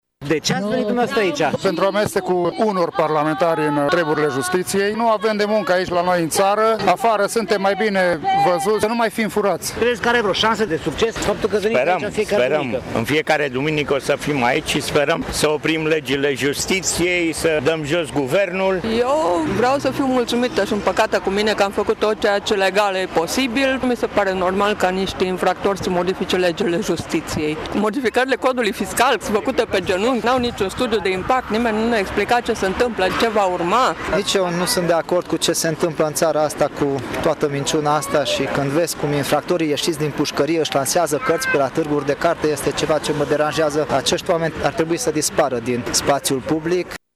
Ca în fiecare din ultimele 4 duminici, protestatarii s-au adunat în jurul orei 18.00 la statuia Latinității din fața Palatului Administrativ și au început să scandeze lozinci antiguvernamentale.